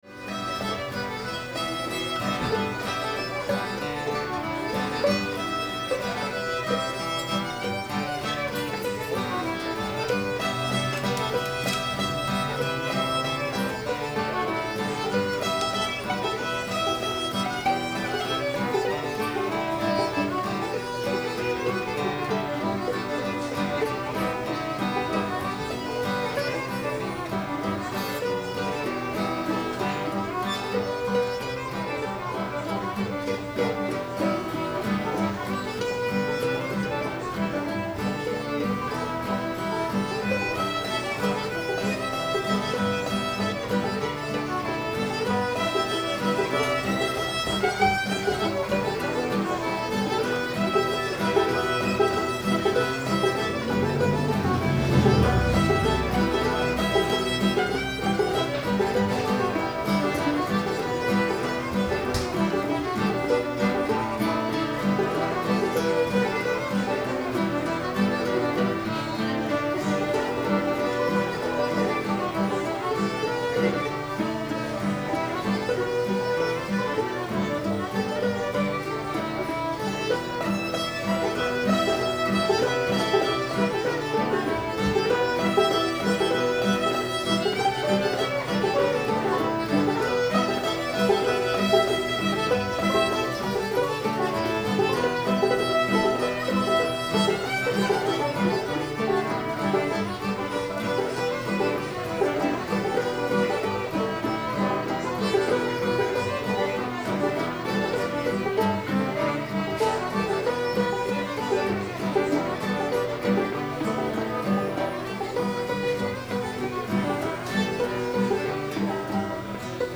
jimmy in the swamp [G]